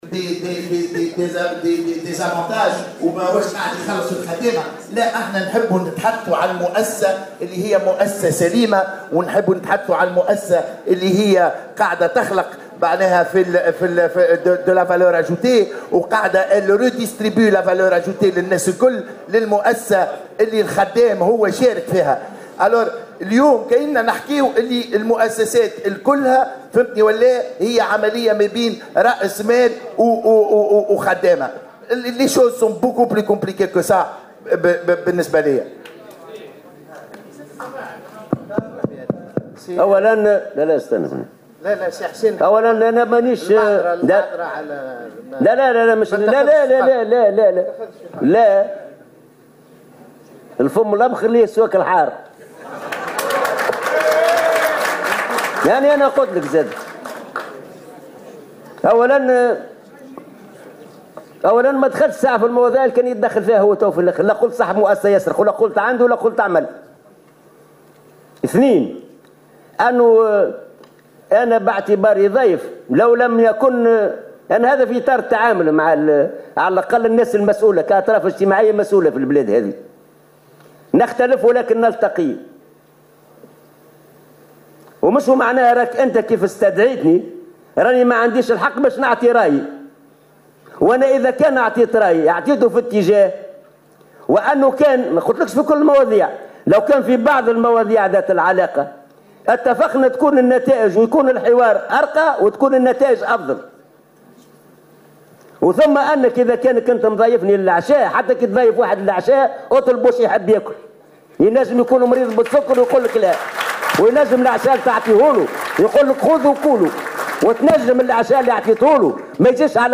نشبت اليوم السبت مشادة كلامية بين الأمين العام للاتحاد العام التونسي للشغل حسين العباسي ورجل أعمال خلال الدورة 29 لتظاهرة أيام المؤسسة التي ينظمها المعهد العربي لرؤساء المؤسسات بسوسة.